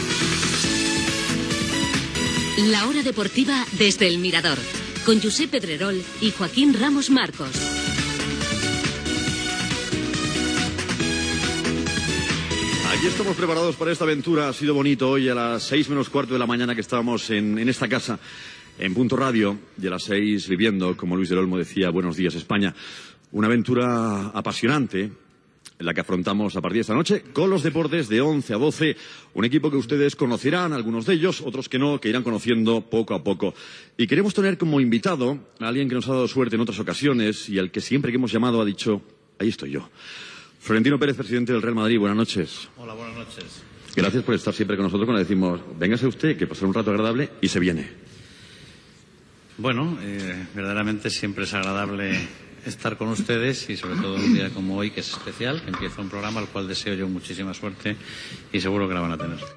Inici del primer programa: careta i salutació al president del Real Madrid Florentino Pérez
FM